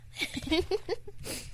Taken from her FullStack episode.